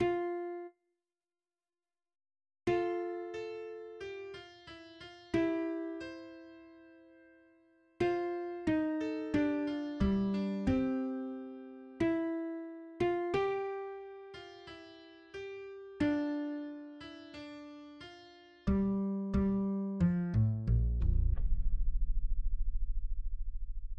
{ << \relative c '' { \new Staff { \set Staff.midiMinimumVolume = #1.5 \set Staff.midiMaximumVolume = #1.8 \set Score.currentBarNumber = #9 \bar "" \tempo 4=90 \key f \major r1 a4 a g8 f e f g4 c2. c4. a8 g f e d g1 g4. f8 e4 g f4. e8 d4 f r1 r1 } } { \new Staff \with {midiInstrument = #"acoustic bass"} { \set Staff.midiMinimumVolume = #1.9 \set Staff.midiMaximumVolume = #1.95 \clef bass \key f \major f4 r2 r4 f1( e1) f4( ees d g, c2) e4.( f8) g1( d1) g,4 g f8 a, bes, bes, a,1 } } >> } \midi{}